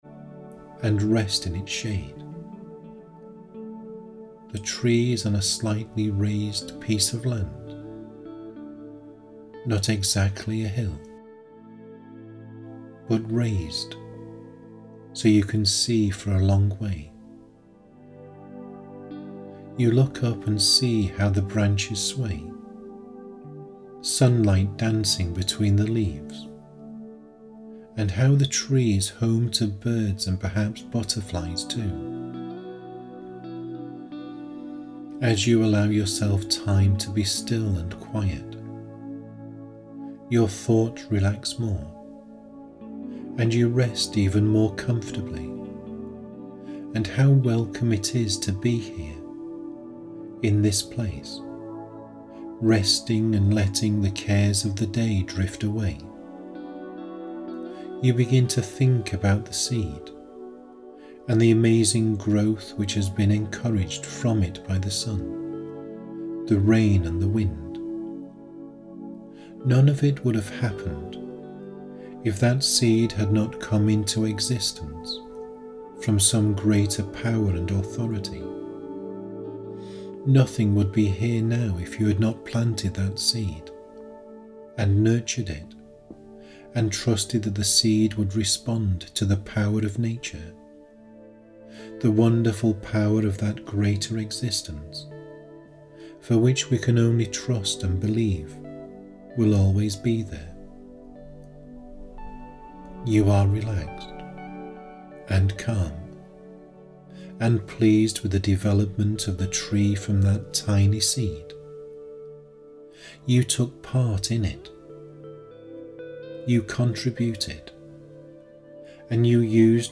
Single Hypnosis track - The YOU Tree
(Session commences after safe listening suggestions)